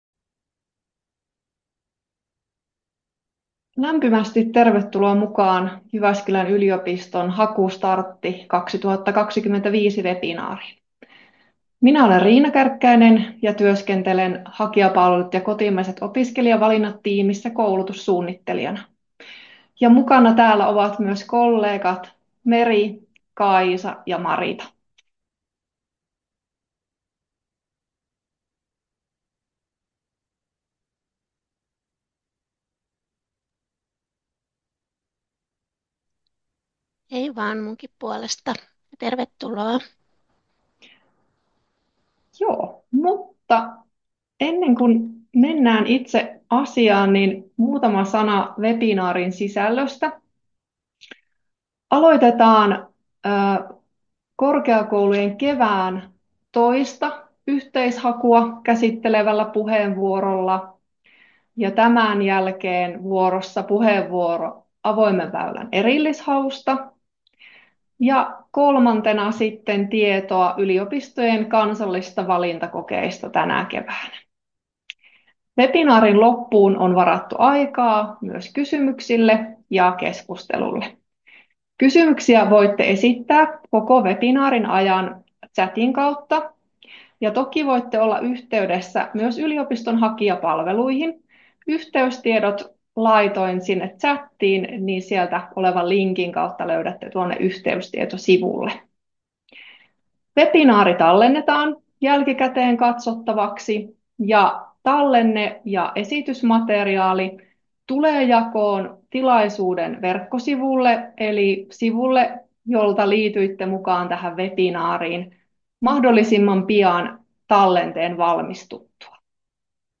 5.3.2025 pidetty webinaari.